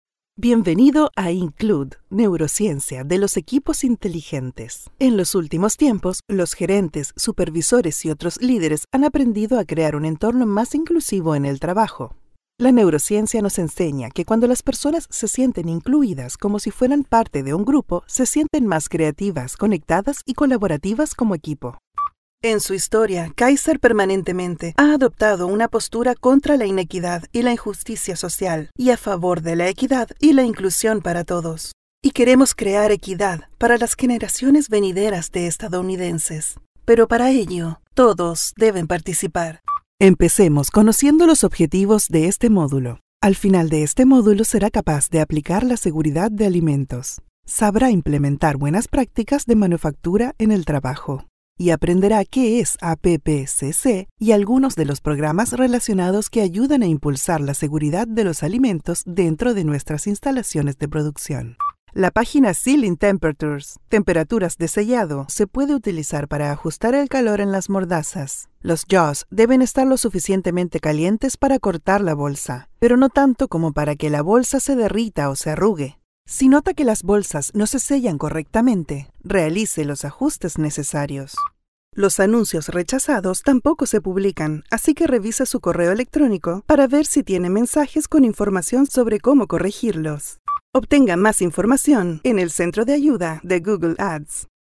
Explainer Videos
I have a versatile and clear Voice in Universal Neutral Spanish.
Young adult or adult female voice with a perfect diction, believable.
I own a professional audio recording studio, with soundproof booth included.